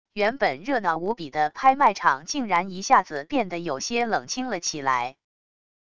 原本热闹无比的拍卖场竟然一下子变得有些冷清了起来wav音频生成系统WAV Audio Player